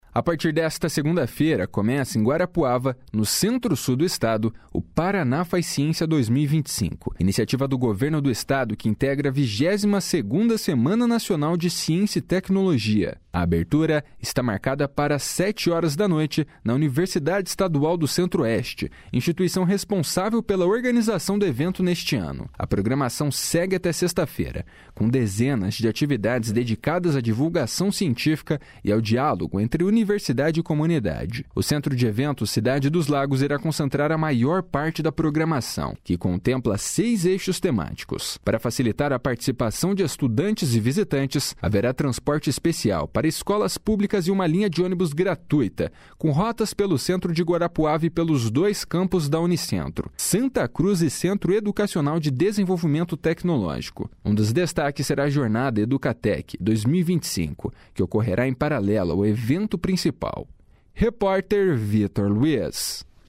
Reportagem
Narração